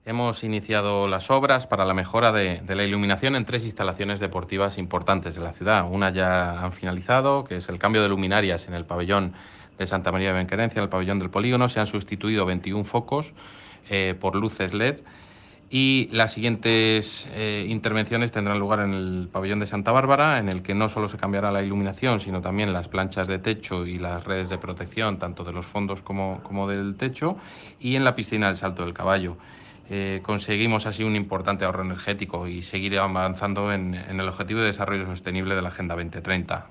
AUDIO. Pablo García, concejal de Deportes
pablo-garcia_mejora-iluminacion-instalaciones-deportivas.mp3